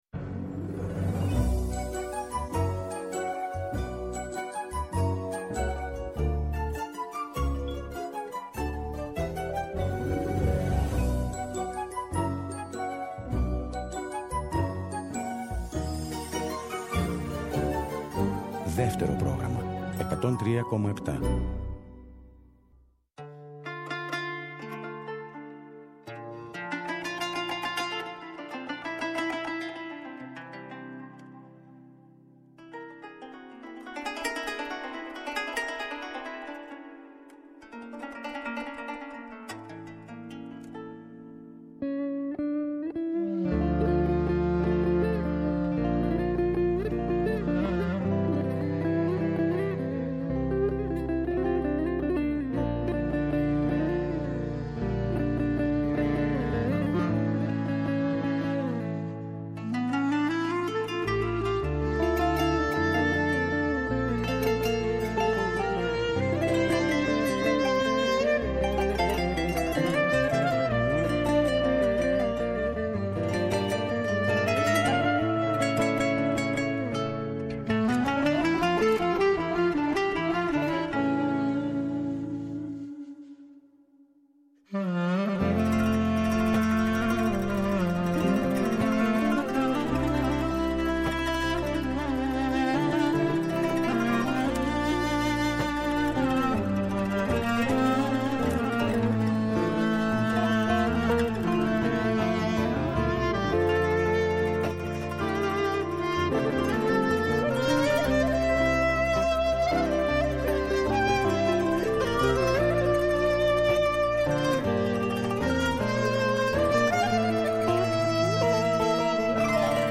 κάθε Σάββατο και Κυριακή στις 19.00 έρχεται στο Δεύτερο Πρόγραμμα με ένα ραδιοφωνικό – μουσικό road trip.